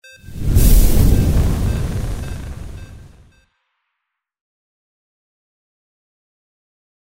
Fire Rune Magic 01
Stereo sound effect - Wav.16 bit/44.1 KHz and Mp3 128 Kbps
previewSCIFI_MAGIC_FIRE_RUNE_WBHD01.mp3